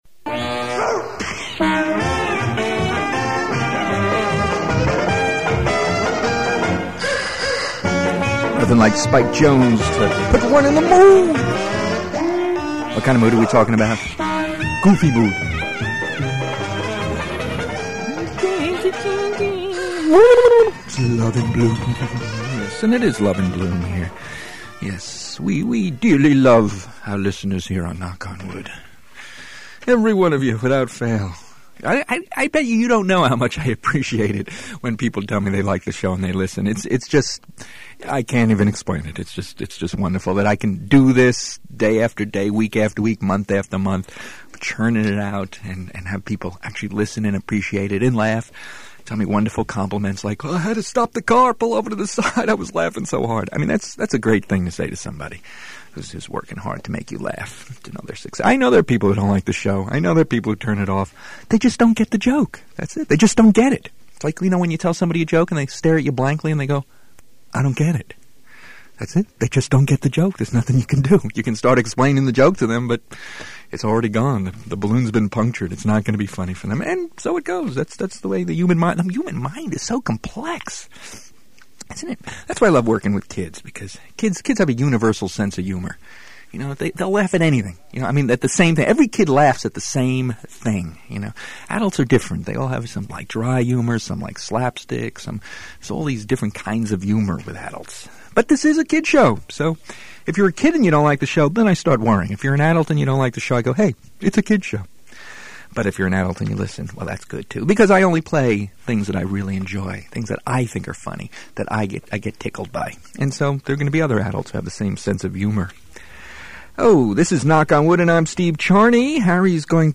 Knock On Wood Comedy Show